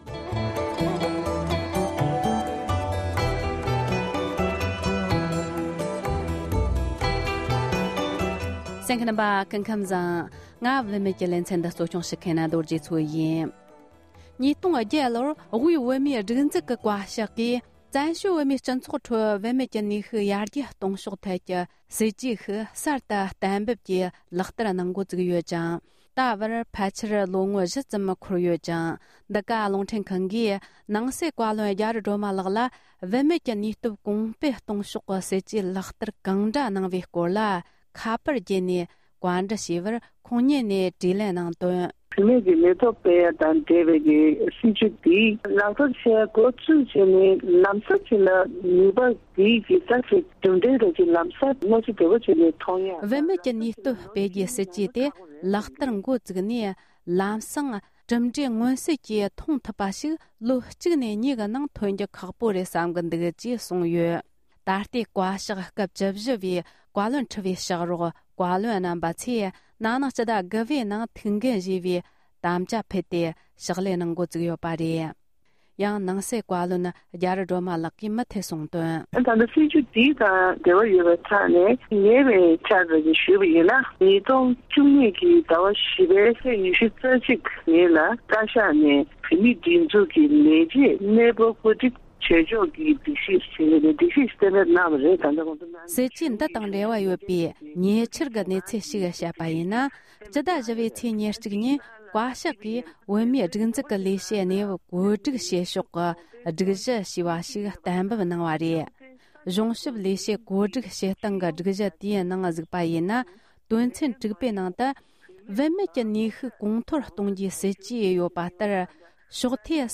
སྒྲ་ལྡན་གསར་འགྱུར།
བཅར་འདྲི་ཞུས་པ་ཞིག